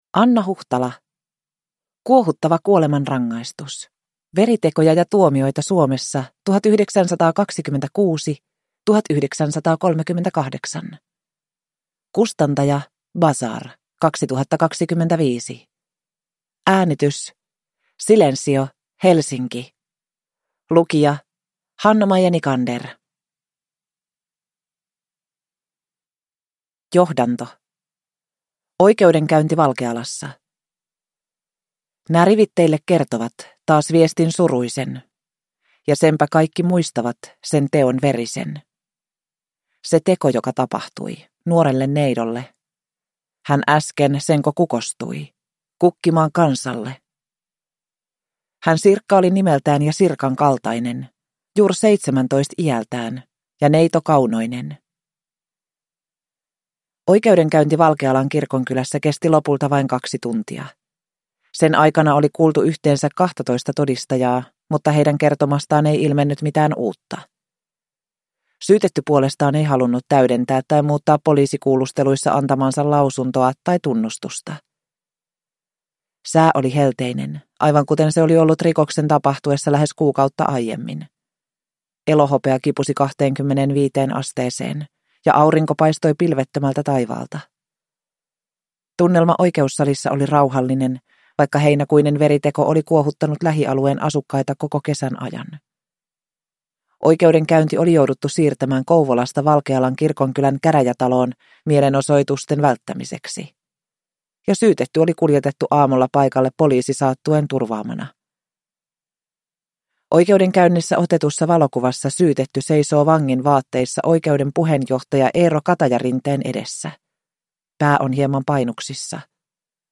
Kuohuttava kuolemanrangaistus – Ljudbok